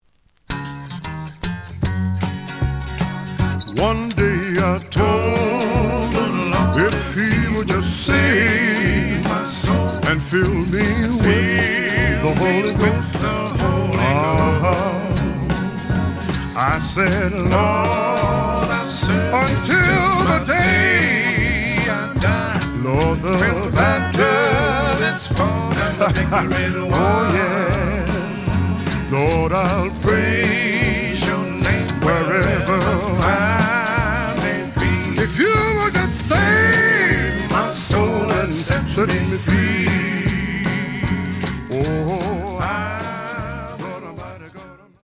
The audio sample is recorded from the actual item.
●Genre: Gospel